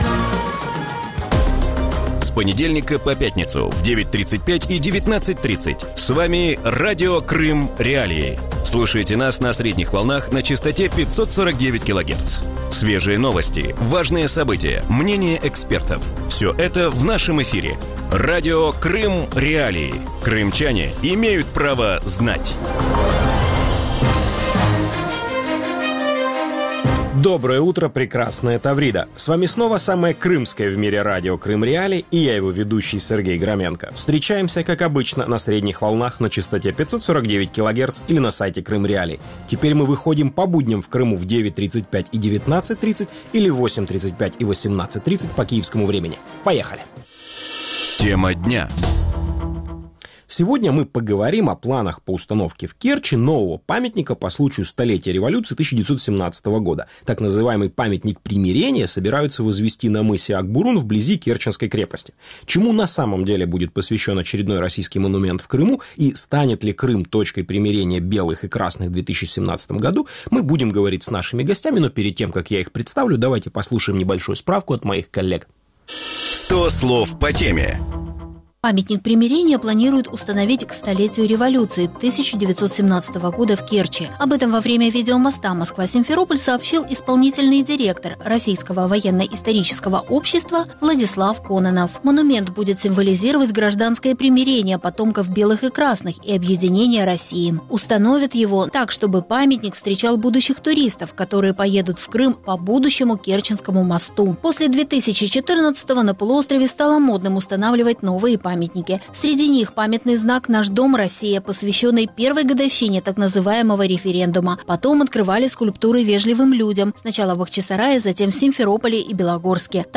В утреннем эфире Радио Крым.Реалии говорят об установке в Керчи нового памятника по случаю 100-летия революции 1917 года.